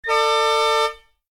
KART_raceStart2.ogg